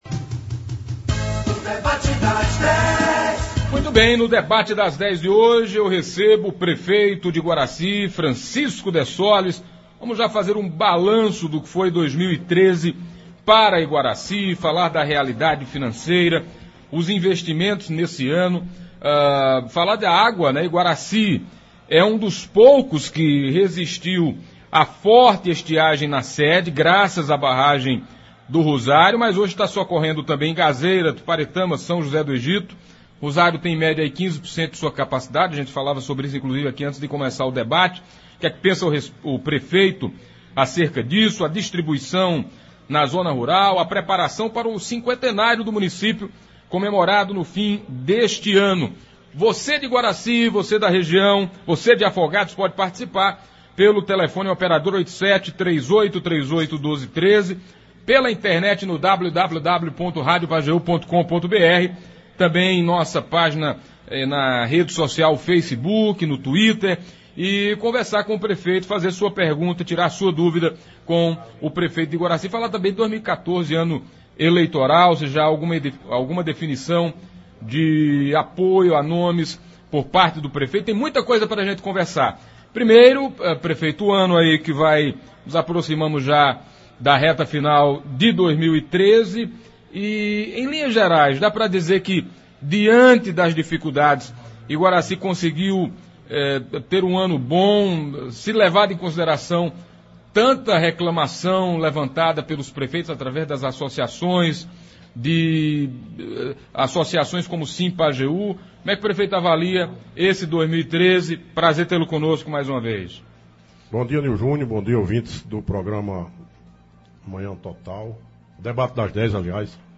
Hoje nos estúdios da Rádio Pajeú, o prefeito de Iguaracy, Francisco Dessoles fez um balanço do seu primeiro ano de gestão.